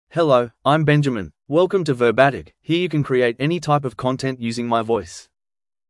Benjamin — Male English (Australia) AI Voice | TTS, Voice Cloning & Video | Verbatik AI
MaleEnglish (Australia)
Benjamin is a male AI voice for English (Australia).
Voice sample
Benjamin delivers clear pronunciation with authentic Australia English intonation, making your content sound professionally produced.